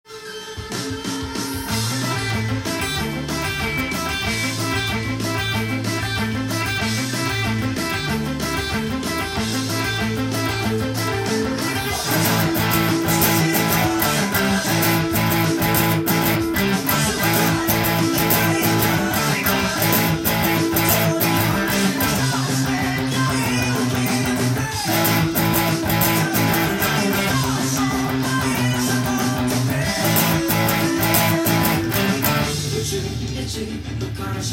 エレキギター用tab譜
音源にあわせて譜面通り弾いてみました
キーボードやベースとユニゾンできるギターtab譜をつくってみました。
イントロのアルペジオが意外と弾きにくく
コードはロックでよく出てくるパワーコードばかりですので